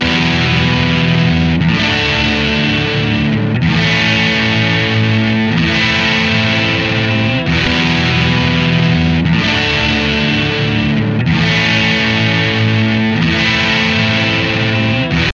Tag: 125 bpm Trap Loops Guitar Electric Loops 2.58 MB wav Key : Unknown